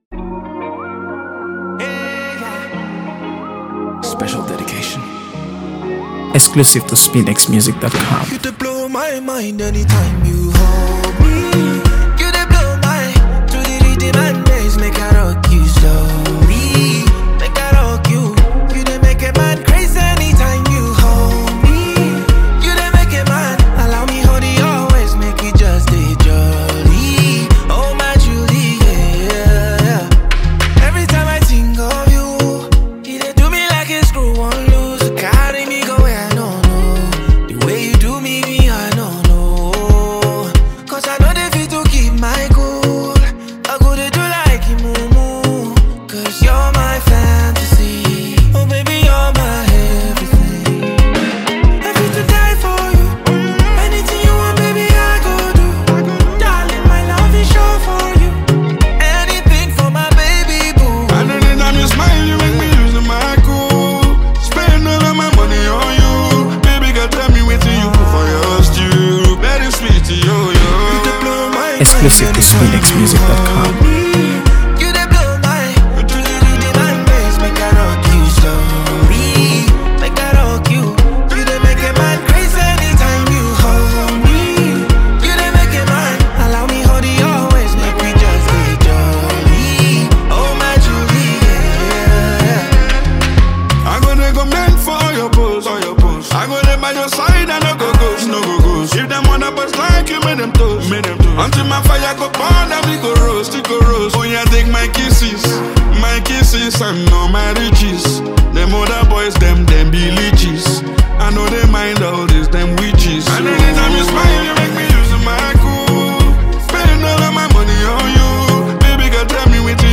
AfroBeats | AfroBeats songs
smooth, soul-infused song
introspective, acoustic-driven sound